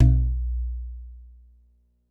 Kicks